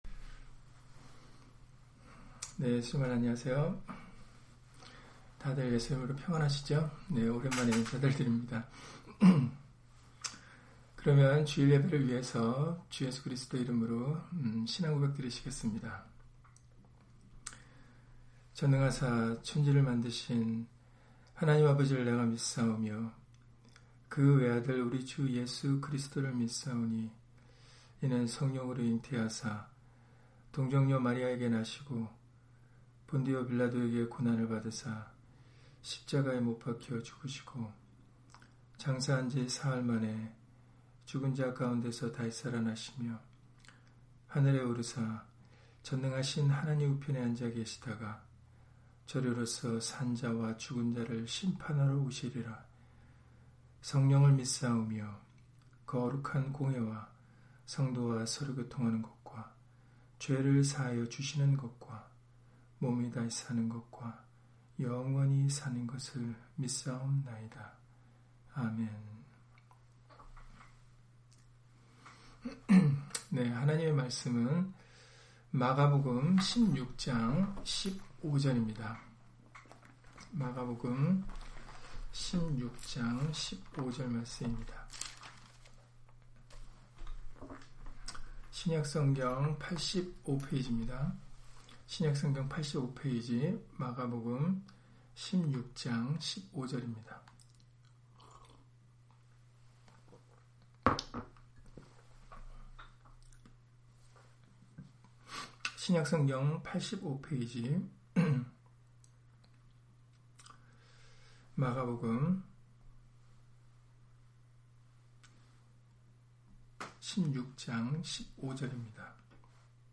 마가복음 16장 15절 [우리는 예수님의 증인들] - 주일/수요예배 설교 - 주 예수 그리스도 이름 예배당